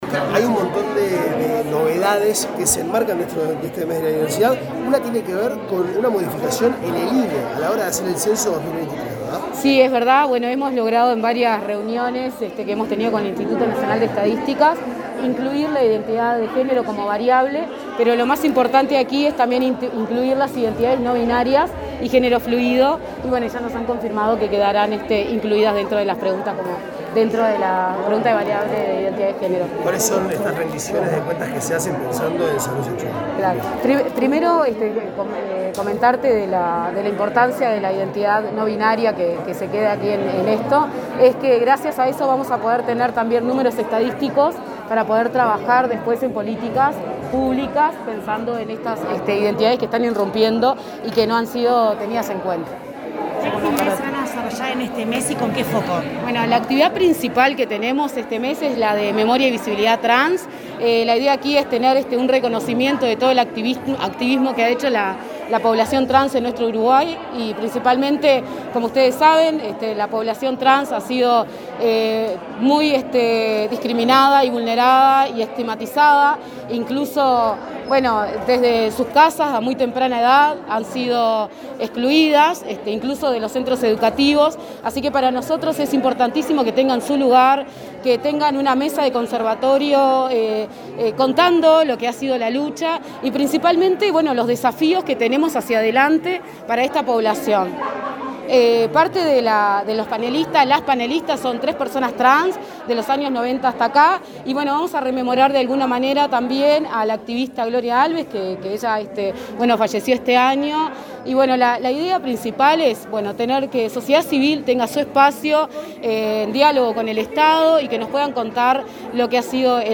Declaraciones a la prensa de la directora del Mides, Rosa Méndez
Tras el evento, la directora de Promoción Sociocultural, Rosa Méndez, fue consultada por medios informativos.